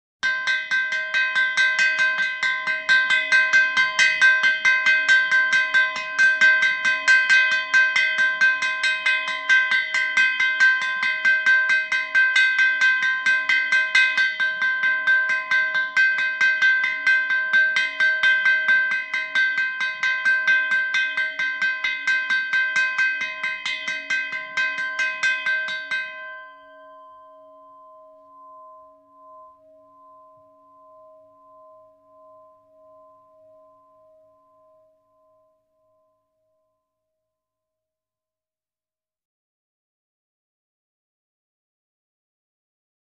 Railroad Crossing | Sneak On The Lot
Railroad Crossing Bell, Close Perspective.